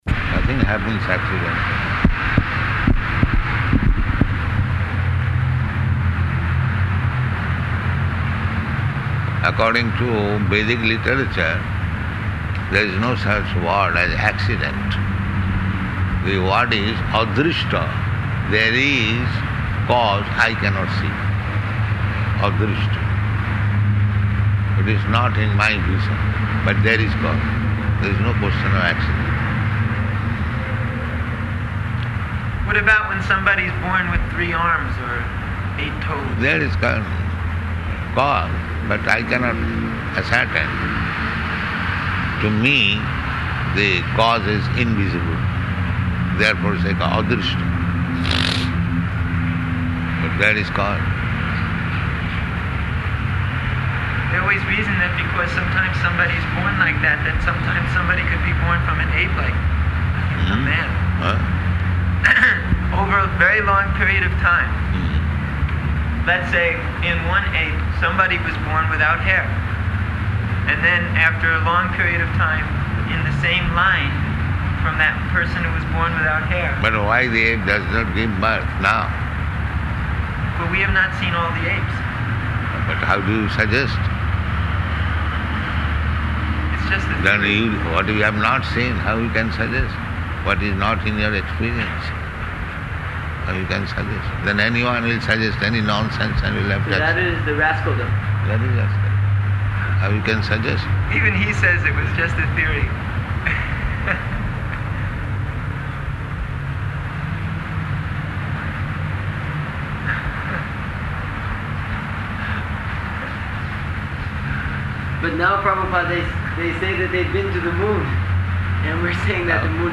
Room Conversation
Room Conversation --:-- --:-- Type: Conversation Dated: October 14th 1975 Location: Johannesburg Audio file: 751014R1.JOH.mp3 Prabhupāda: Nothing happens accidentally.